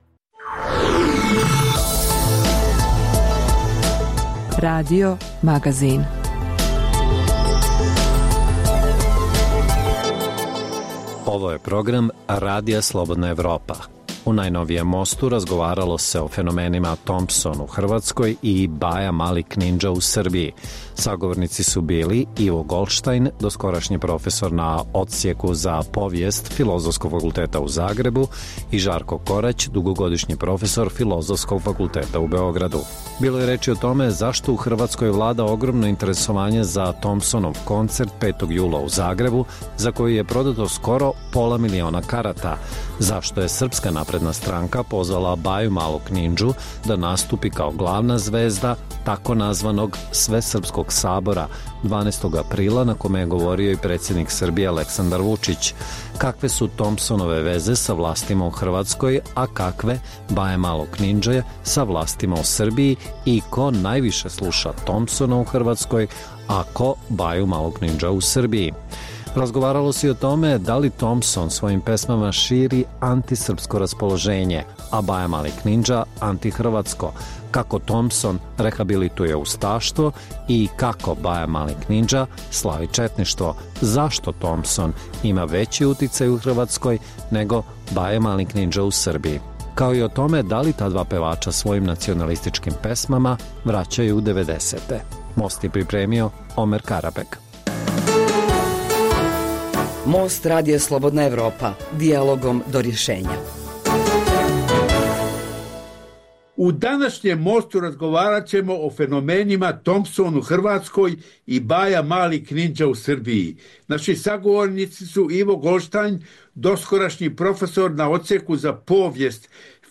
Emisija o putu BiH ka Evropskoj uniji i NATO sadrži vijesti, analize, reportaže i druge sadržaje o procesu integracije.